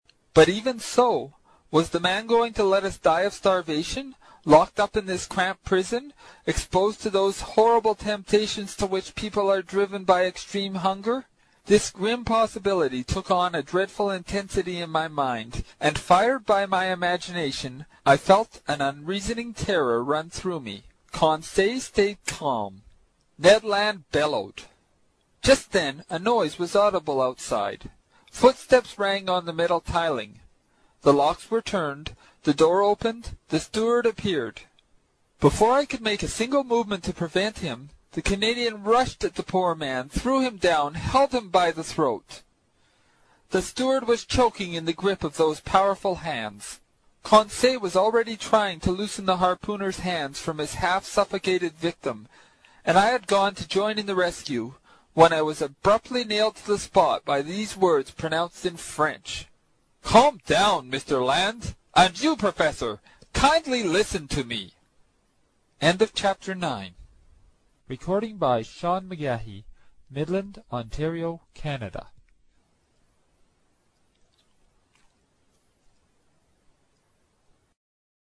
在线英语听力室英语听书《海底两万里》第129期 第9章 尼德兰的愤怒(14)的听力文件下载,《海底两万里》中英双语有声读物附MP3下载